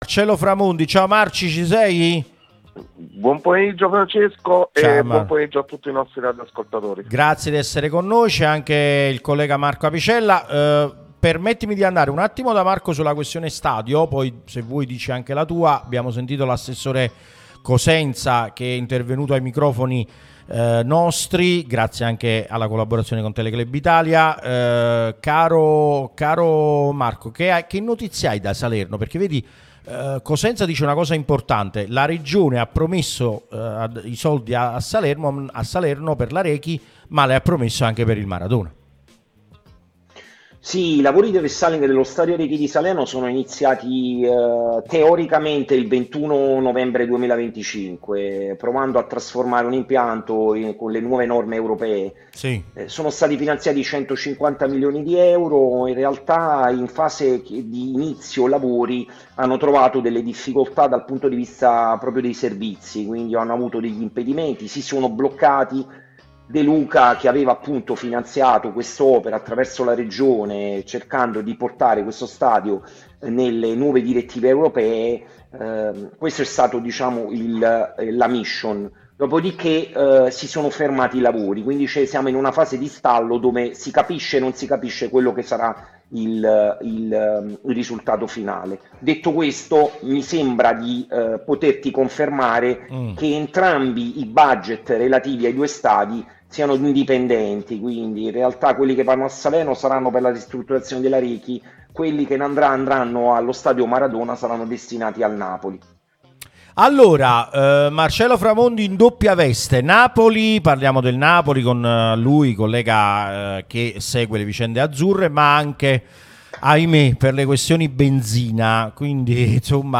l'unica radio tutta azzurra e live tutto il giorno